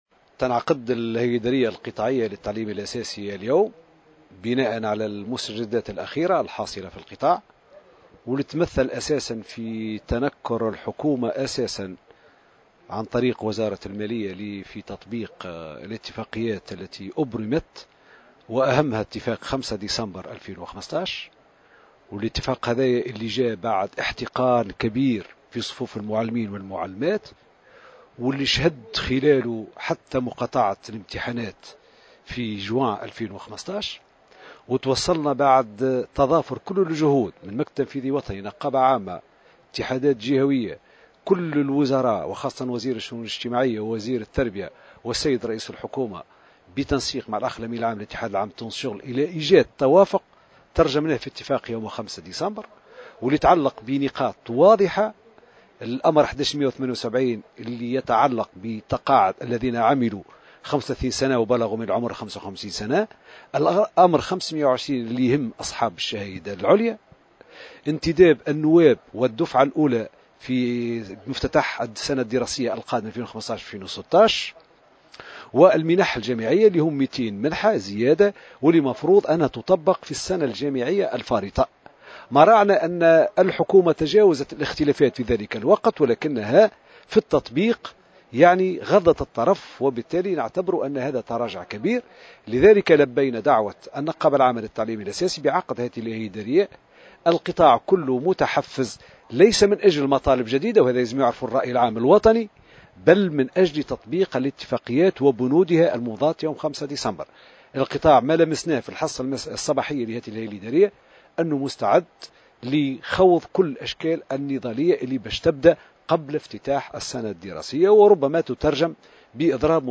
وأوضح في تصريح لمراسلة "الجوهرة أف أم" أن الحكومة تراجعت عن التزاماتها وعن تطبيق الاتفاقيات المبرمة وأهمها اتفاق يوم 5 ديسمبر 2015 والتي تهم أساسا تسوية وضعية المتقاعدين الذين عملوا 35 سنة وبلغوا من العمر 55 سنة و انتداب الدفعة الأولى من النواب في مفتتح من السنة الدراسية القادمة، إضافة إلى الزيادة في المنح الجامعية المسندة لفائدة أبناء المعلمين.